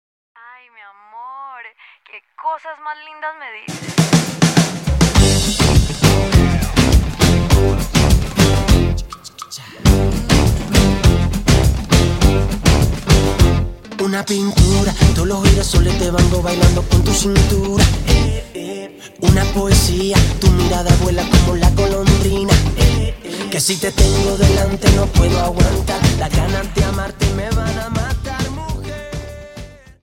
Dance: Samba